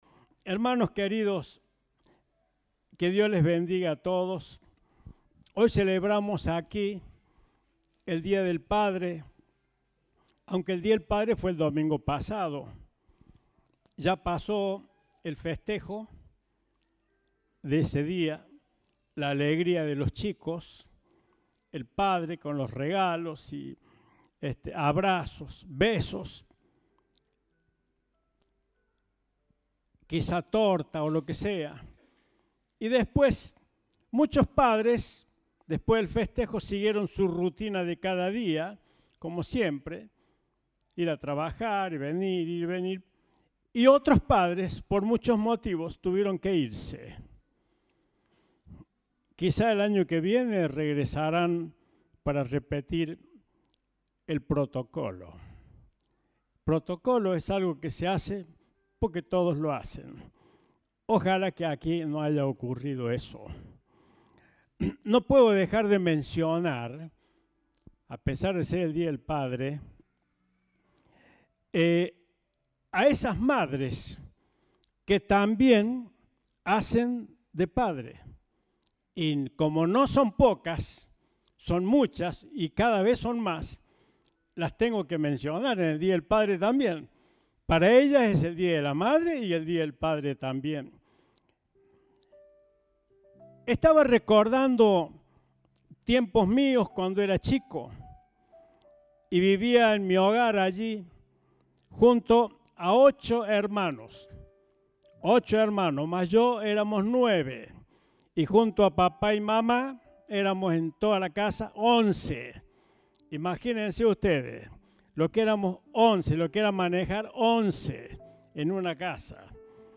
Compartimos el mensaje del Domingo 26 de Junio de 2022.